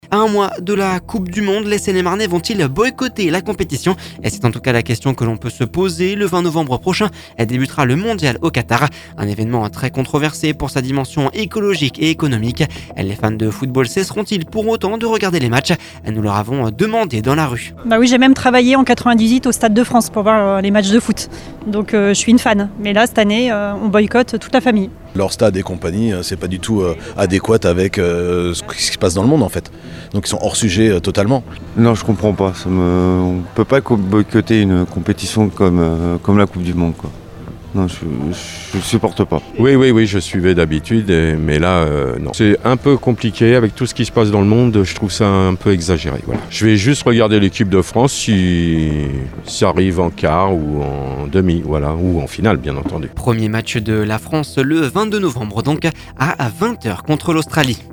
Les fans de football cesseront-ils pour autant de regarder les matchs, nous leur avons demandé dans la rue.